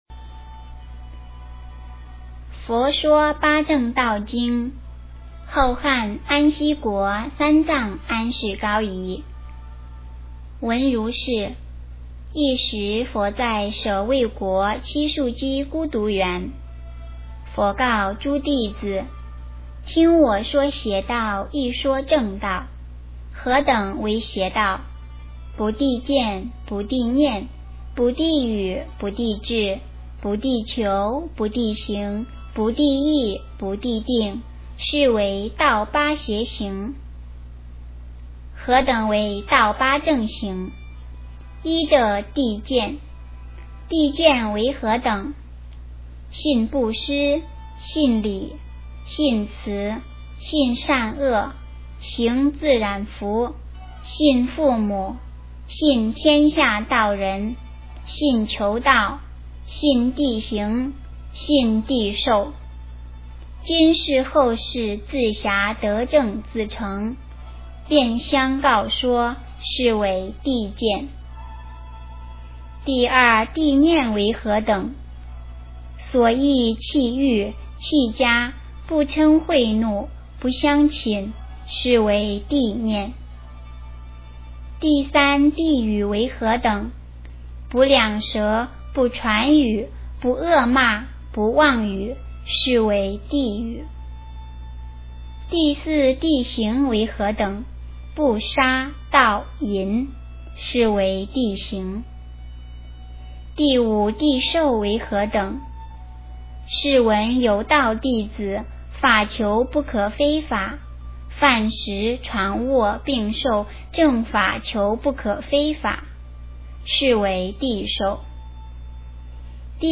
诵经
佛音 诵经 佛教音乐 返回列表 上一篇： 圣无量寿决定光明王陀罗尼 下一篇： 往生咒 相关文章 时光不忘 时光不忘--霍尊...